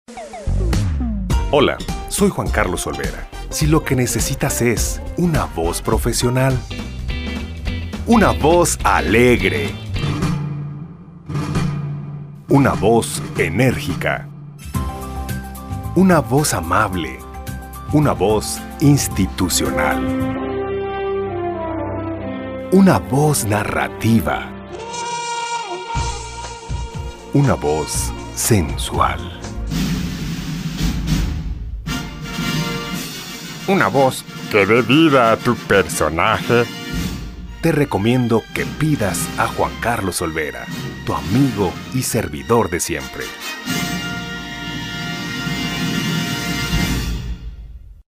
kastilisch